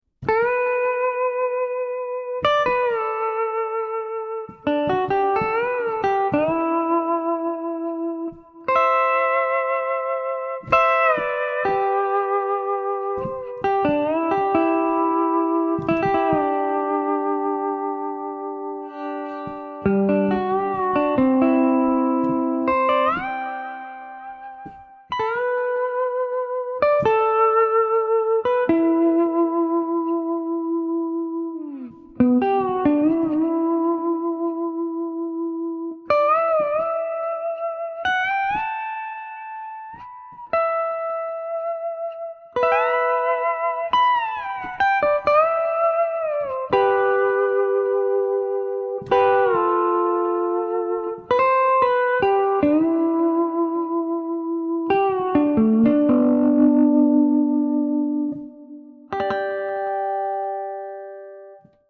The Magic 🪄sound of our virtual LapSteel guitar.
It's a beautiful Jedson LapSteel ❤